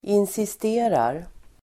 Uttal: [insist'e:rar]